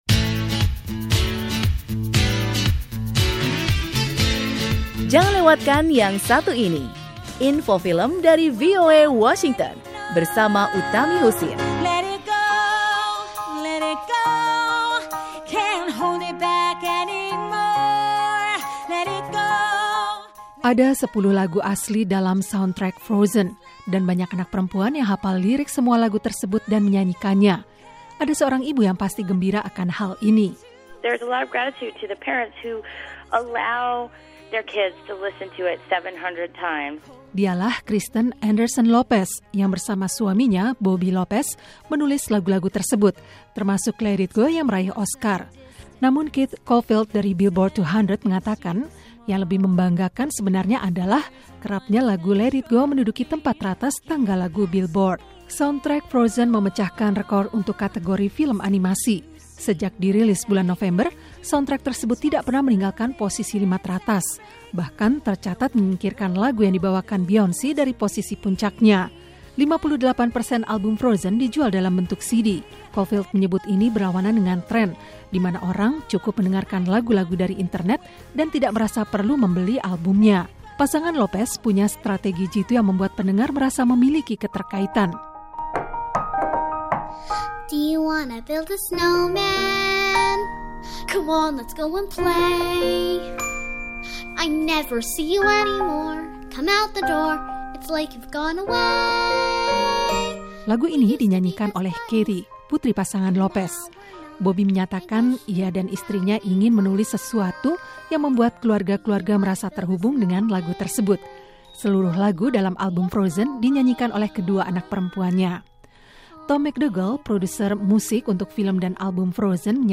Film produksi Disney, Frozen, mengisahkan seorang anak perempuan menaklukkan es dan salju untuk menyelamatkan kerajaannya dari musim dingin abadi. Lagu-lagu yang mengisi film animasi peraih Oscar tersebut juga ternyata telah “menaklukkan” Billboard 200, dengan menempati posisi teratas tangga lagu itu dengan rekor 13 kali. Laporan wartawan VOA